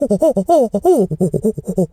monkey_chatter_14.wav